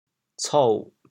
“脞”字用潮州话怎么说？
脞 部首拼音 部首 月 总笔划 11 部外笔划 7 普通话 cuǒ 潮州发音 潮州 co3 文 潮阳 cou3 文 澄海 co3 文 揭阳 co3 文 饶平 co3 文 汕头 co3 文 中文解释 潮州 co3 文 对应普通话: cuǒ ①琐细的，繁杂的：丛～（细碎，烦琐） | ～说（脞言，脞谈，脞语。